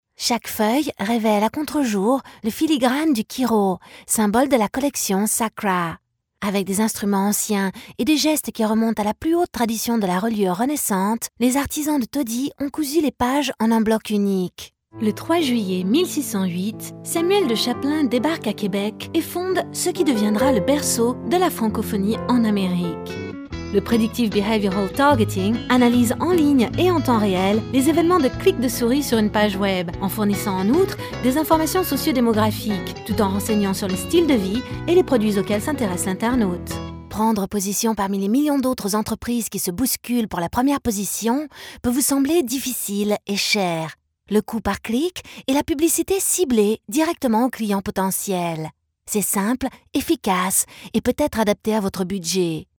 franzĂ¶sische Sprecherin.
She own a recording studio of good quality (Neumann microphone, iso-booth, ISDN).
Sprechprobe: Industrie (Muttersprache):
Native female French voice talent.